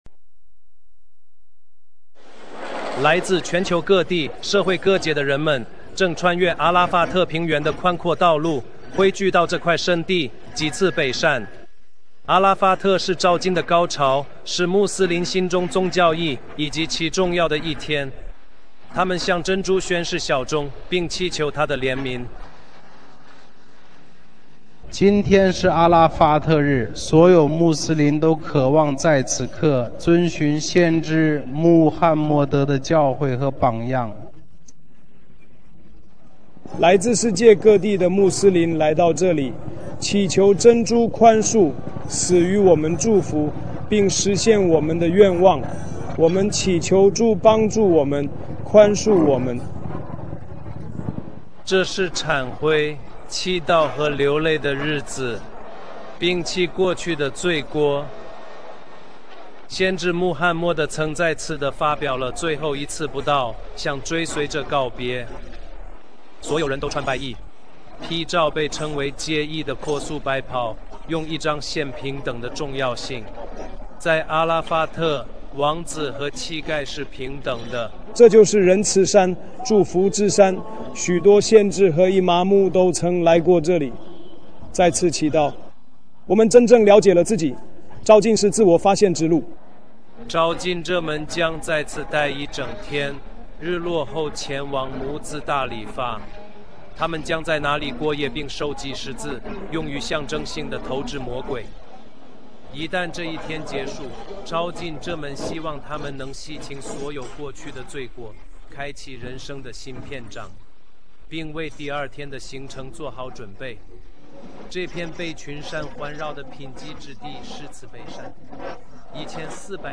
属性: 本视频为半岛电视台（Al-Jazeera）关于朝觐（Hajj）功德的新闻报道。